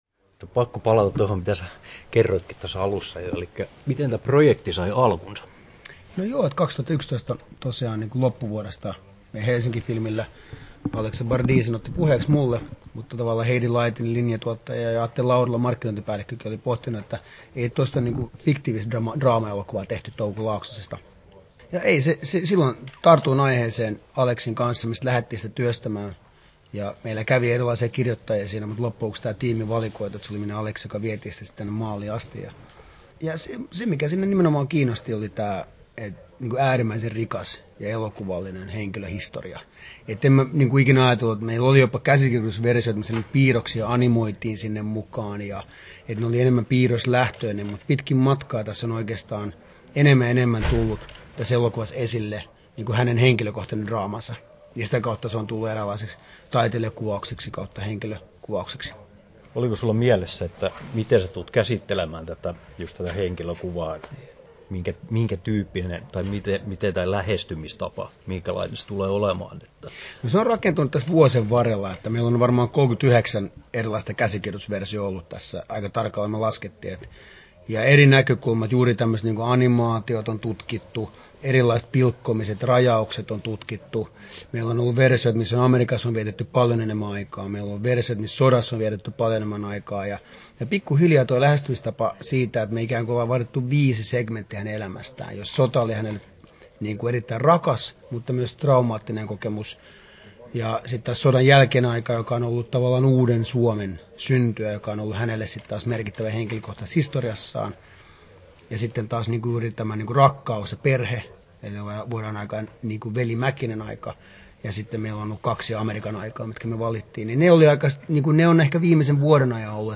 Haastattelussa Dome Karukoski Kesto: 10'28" Tallennettu: 15.02.2017, Turku Toimittaja